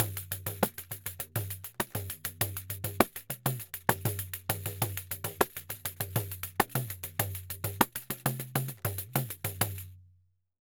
Pandeiro 1_Samba 100_3.wav